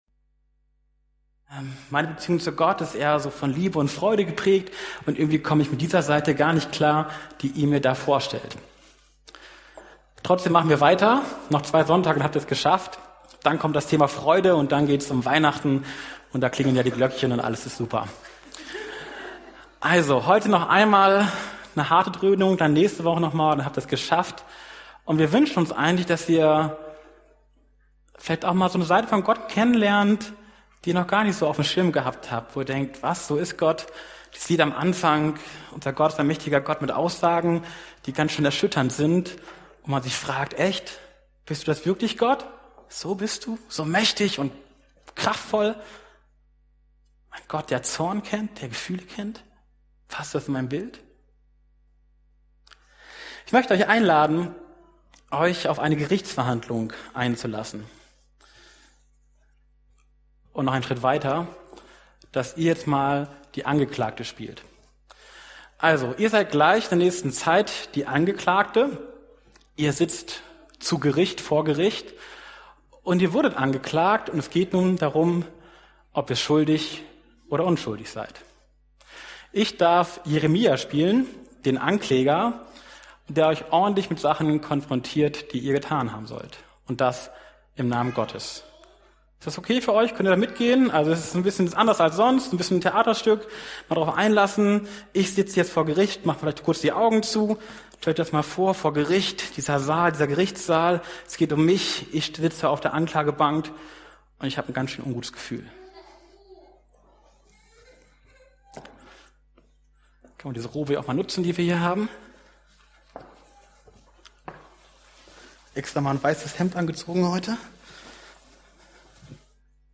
November 2019 Ausgetauscht gegen einen Anderen Prediger(-in)